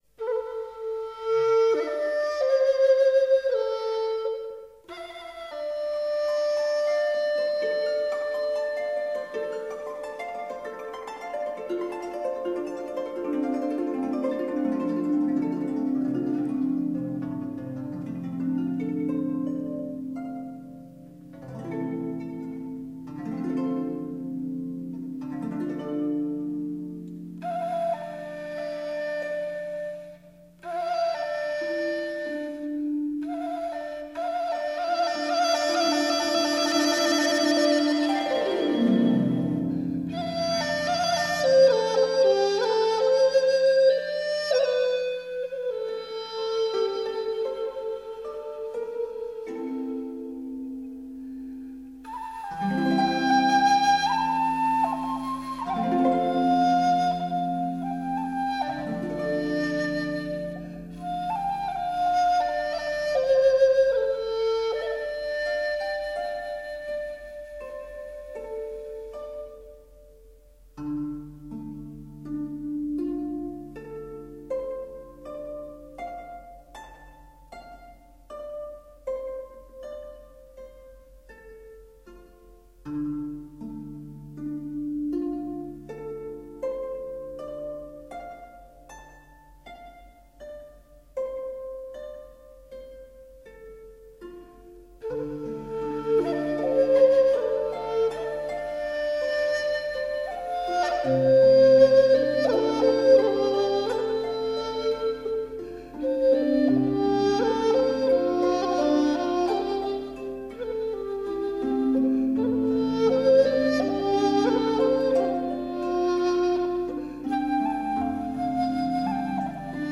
江南丝竹音乐风格轻巧、明朗、 欢快、活泼，具有浓郁的江南乡土气息和艺术魅力 。
琵琶